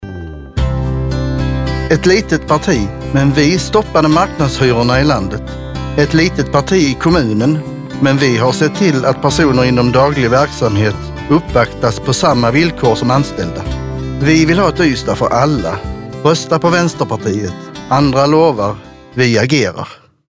Vår valrörelse sträcker sig även ut i lokalradion!